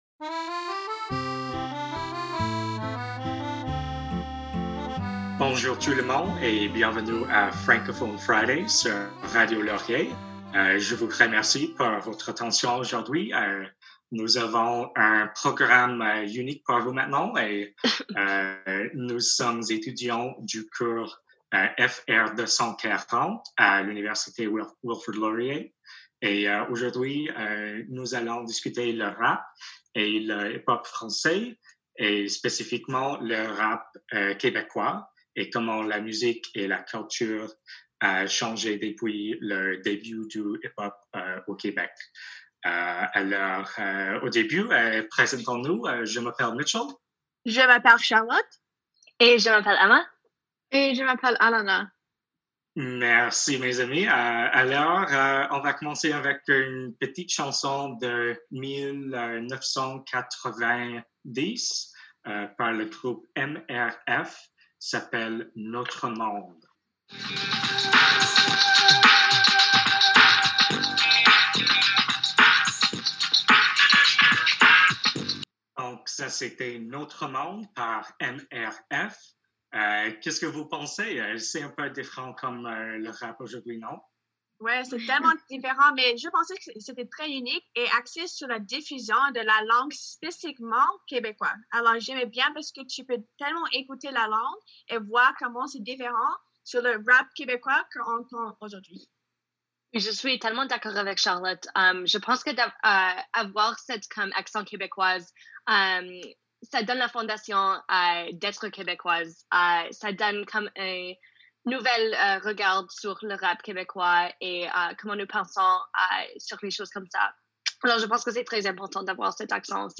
On this week’s edition of Francophone Friday, four Wilfrid Laurier students will be discussing Quebecois hip hop, and the connection between language and cultural expression in Quebec. The group will touch on the beginning of Quebecois hip hop in the early 1990s, and explore how the music has both changed, and stayed the same in the 30 years since its debut.